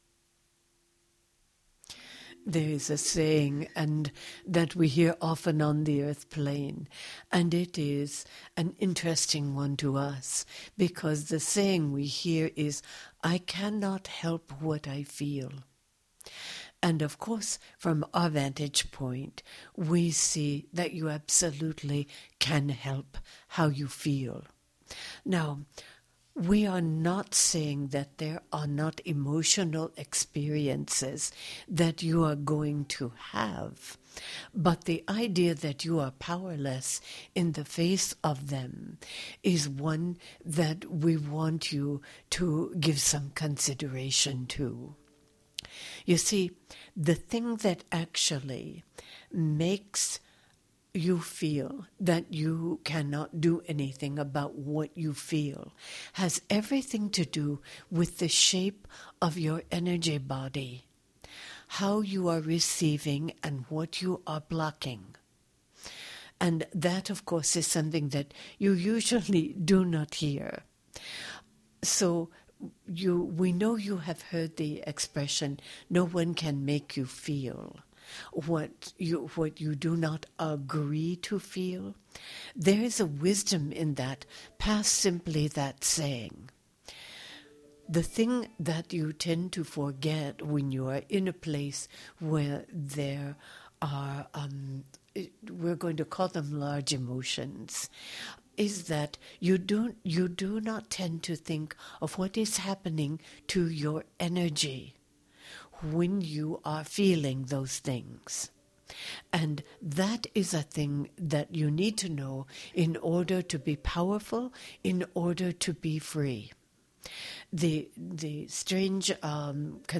Genre: Meditation.